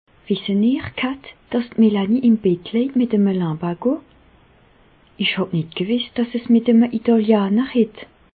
Bas Rhin
Ville Prononciation 67
Reichshoffen